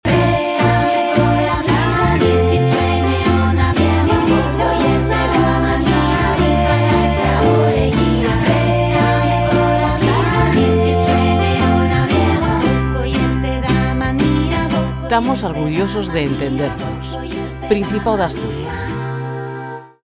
Cuña radio eonaviegu.